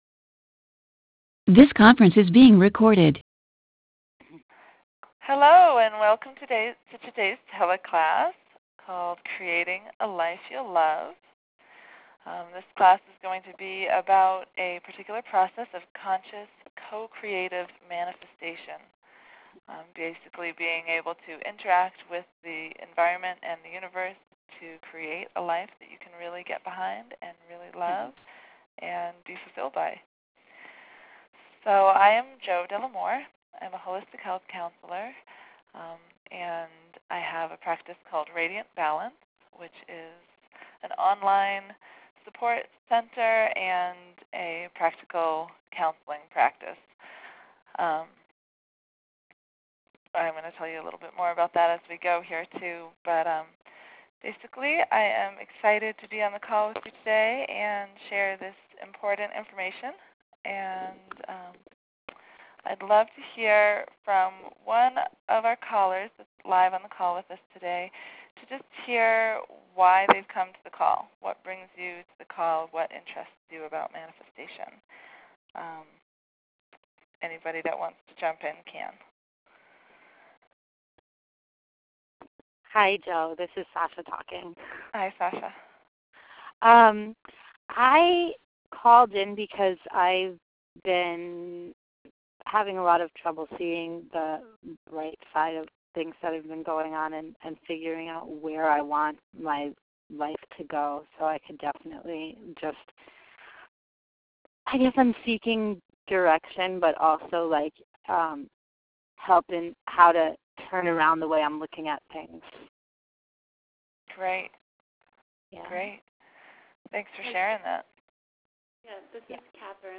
Recorded Teleclasses